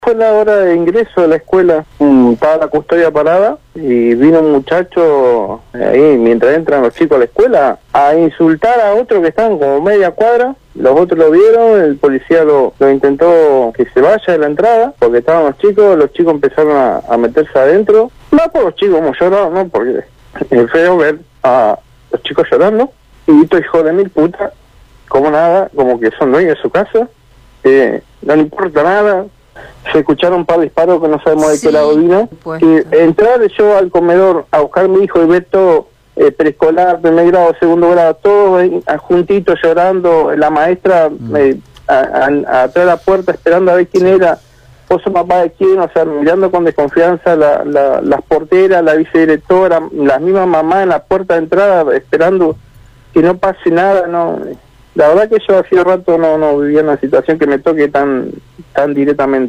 «A estos hijos de puta no les importa nada, porque hay chicos, padres y madres y aún así disparan a matar. Se escucharon varios disparos pero no sabíamos de dónde venían«, finalizó entre lágrimas de impotencia el padre de un alumno, a quien le genera bronca este tipo de situaciones y todos los días ruega porque a los menores no les suceda nada.